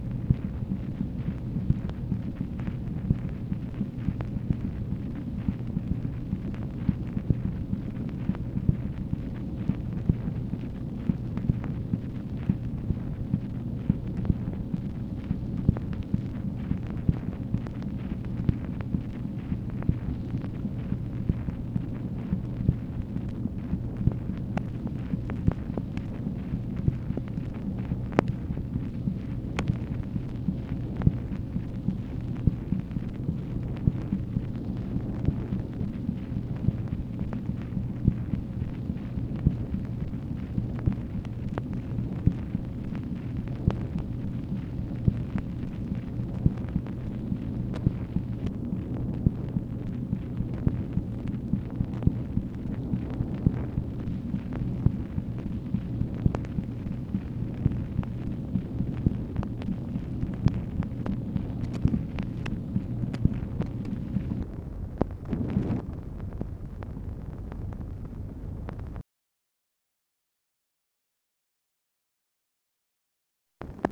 MACHINE NOISE, October 22, 1965
Secret White House Tapes | Lyndon B. Johnson Presidency